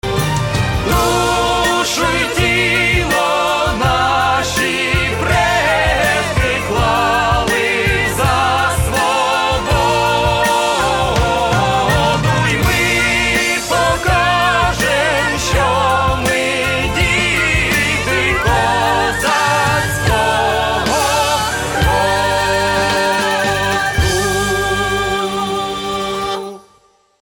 Updated Ukrainian anthem, performed by Vertep